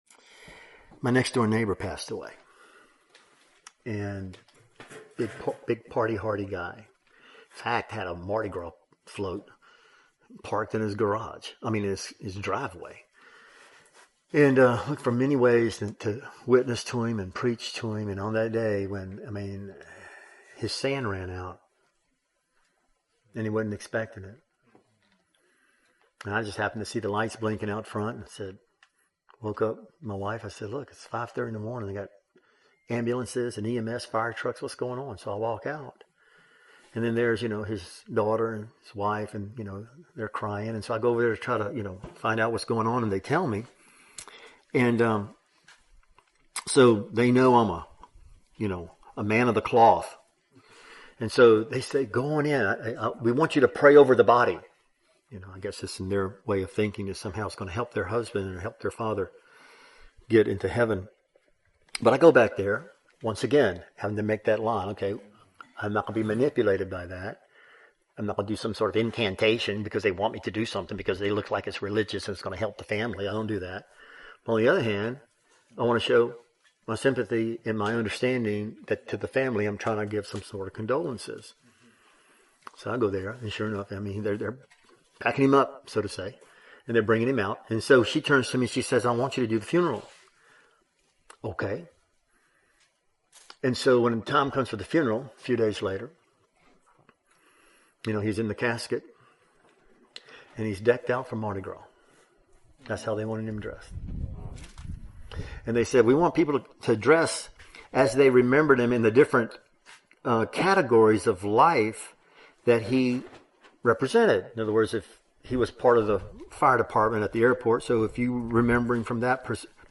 This excerpt was taken from the full sermon, “ Transformation For Proclamation (Part 4) “.